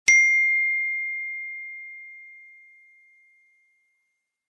알림음 iPhone ding
iphone_ding.mp3